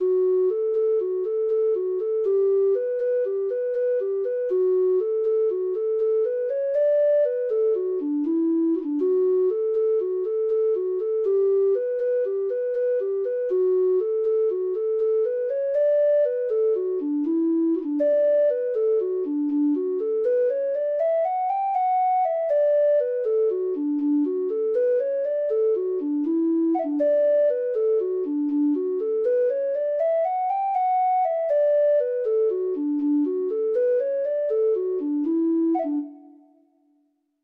Free Sheet music for Treble Clef Instrument
Traditional Music of unknown author.
Irish Slip Jigs